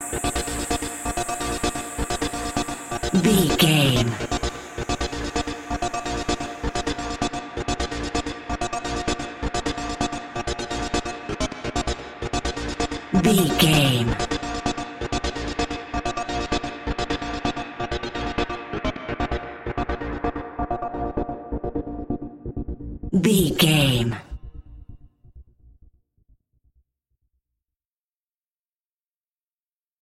Epic / Action
Fast paced
Aeolian/Minor
B♭
aggressive
powerful
dark
driving
energetic
drum machine
synthesiser
breakbeat
synth leads
synth bass